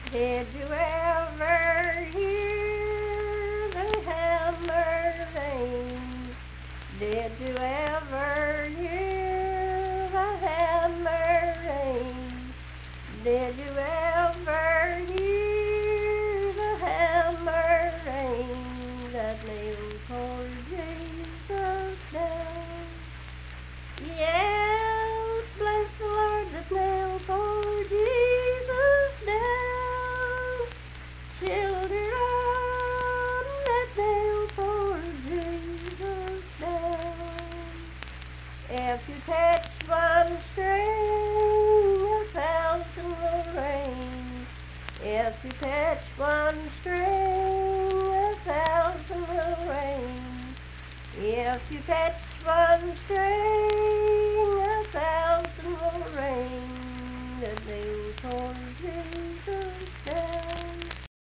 Unaccompanied vocal music performance
Verse-refrain 2(4-8).
Hymns and Spiritual Music
Voice (sung)